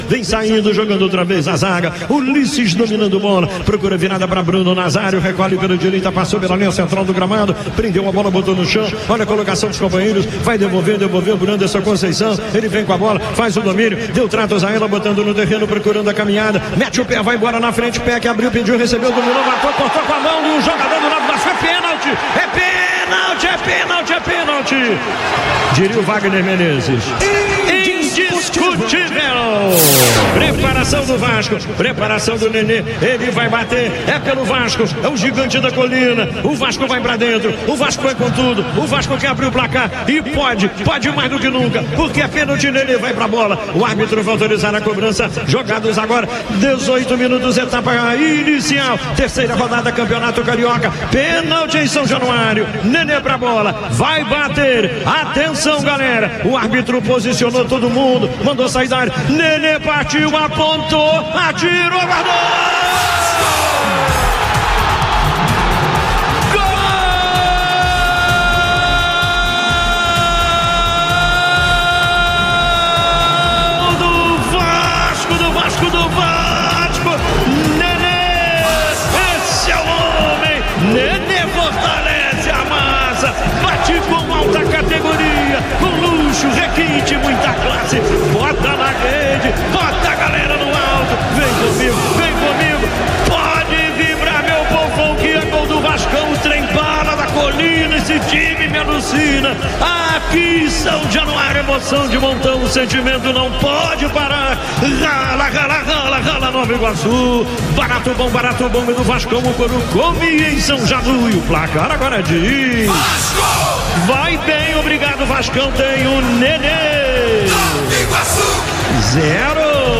GOL-VASCO-1-X-0-NOVA-IGUACU-online-audio-converter.com_.mp3